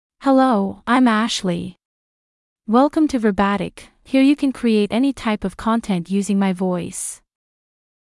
Ashley — Female English (United States) AI Voice | TTS, Voice Cloning & Video | Verbatik AI
Ashley is a female AI voice for English (United States).
Voice sample
Ashley delivers clear pronunciation with authentic United States English intonation, making your content sound professionally produced.